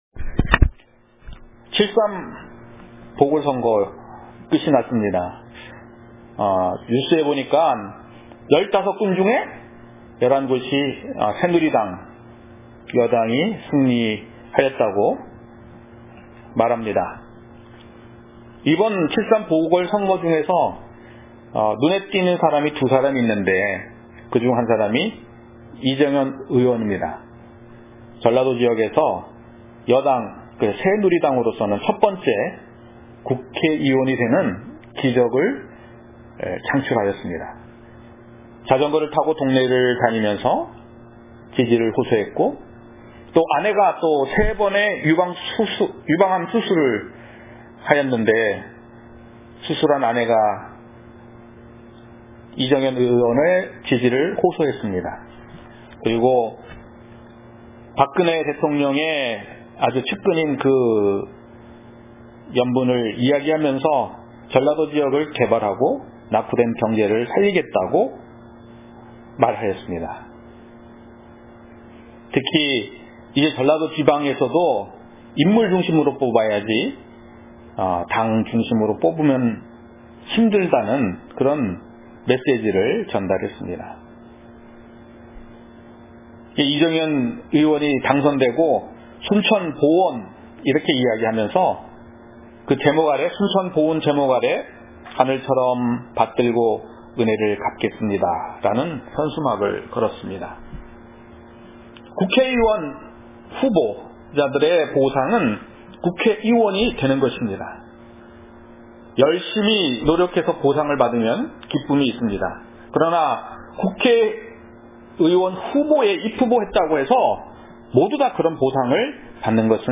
Categories 설교